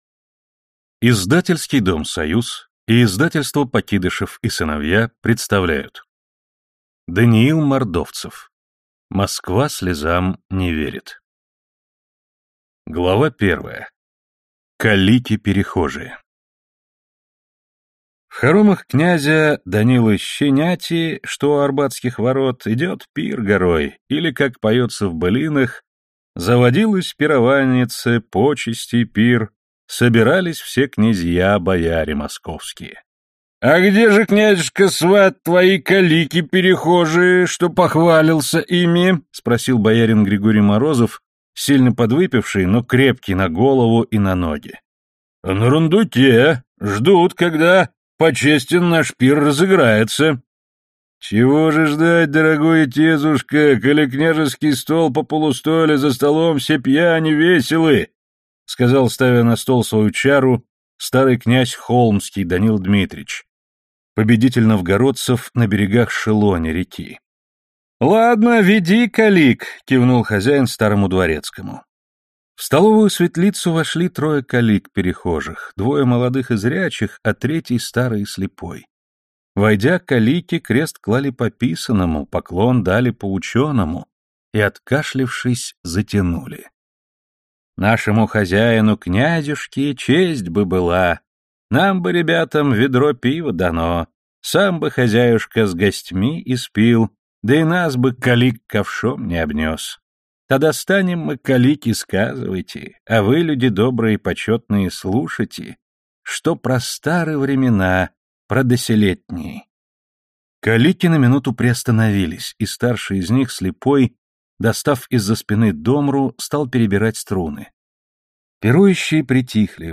Аудиокнига Москва слезам не верит | Библиотека аудиокниг